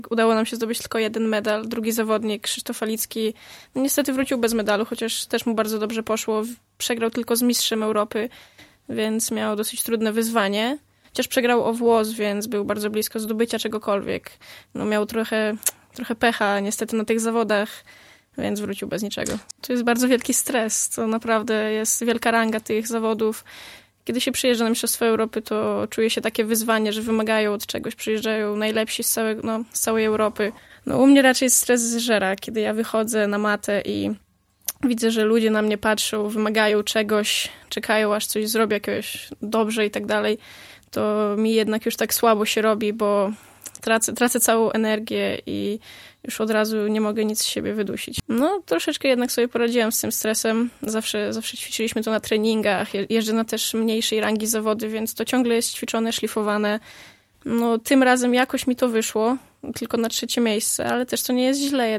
We wtorek (18.04) medalistkę gościliśmy w Radiu 5, gdzie opowiedziała o mistrzostwach.